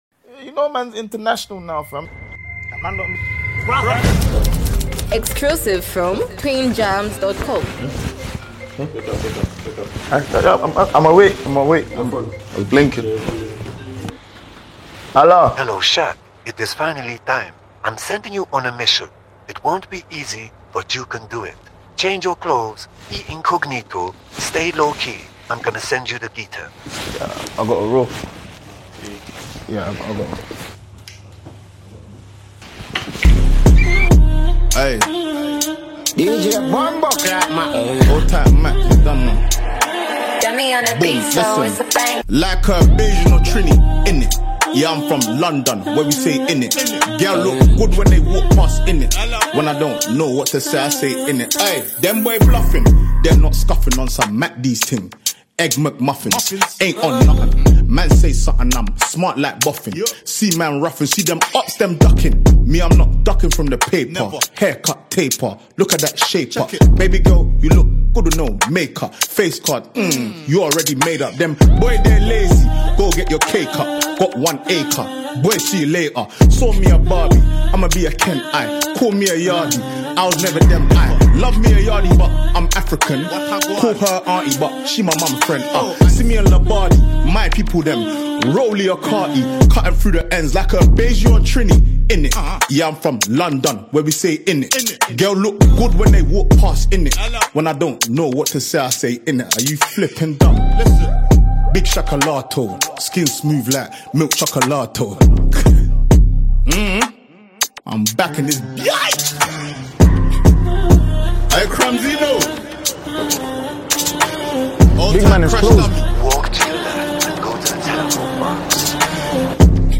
brings his signature playful delivery and catchy ad-libs